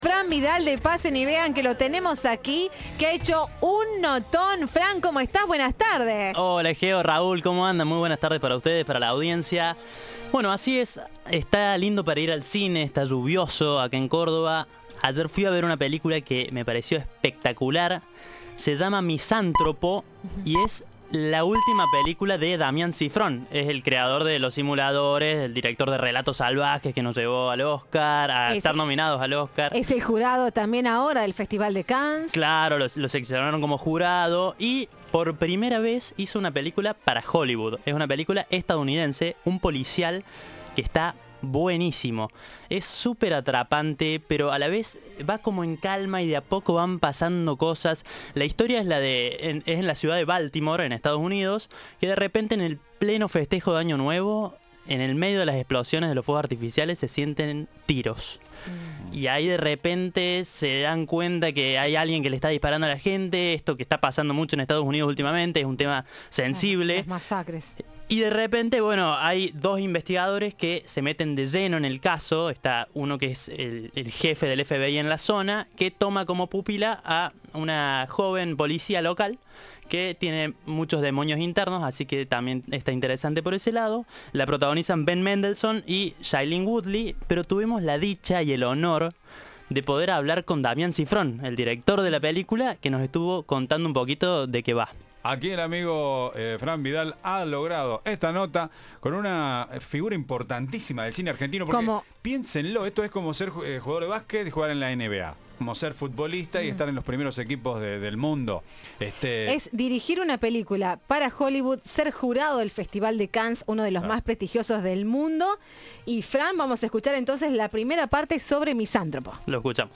Audio. Damián Szifron habló con Cadena 3 sobre su nueva película, Los Simuladores y más